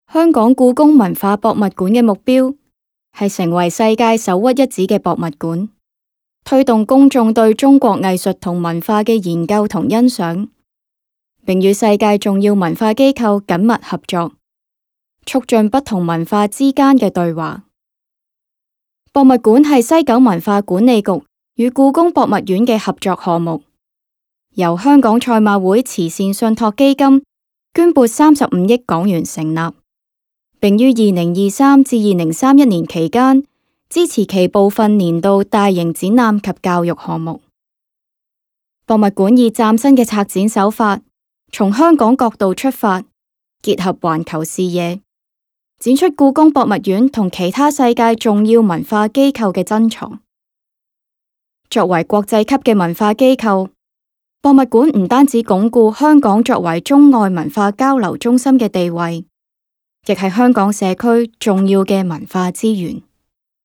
Theatre House Announcement - Cantonese, Mandarin & English
Voix off